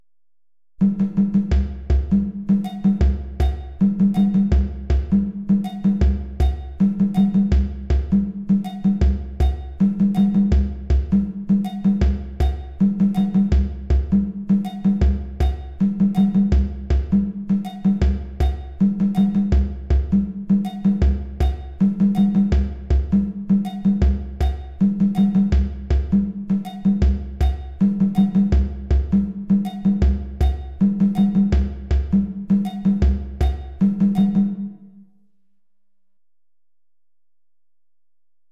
Lead drum + bell (audio)                   Lead drum, bell, + rattle (audio)
Sequence_B_Lead_drum_+_bell.mp3